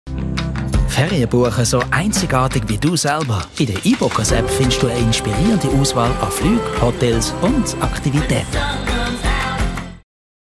Werbung Schweizerdeutsch (ZH)